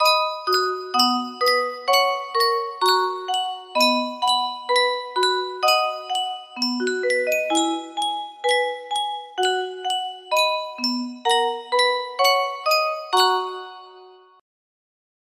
Yunsheng Music Box - Jolly Old St. Nicholas Version B Y169 music box melody
Full range 60